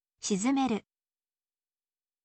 shizumeru